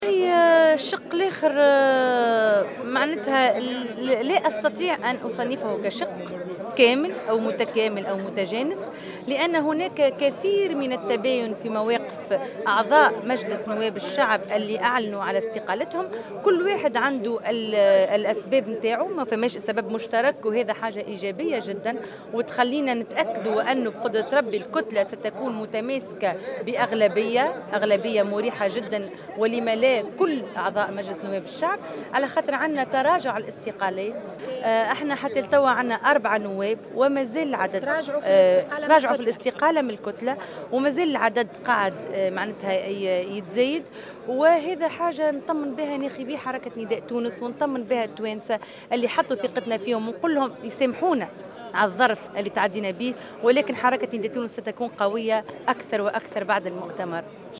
أكدت النائب عن حزب نداء تونس، أنس الحطاب في تصريح إعلامي قبل اجتماع الهيئة التأسيسية للحزب تراجع 4 نواب عن قرار الاستقالة من كتلة نداء تونس بمجلس نواب الشعب.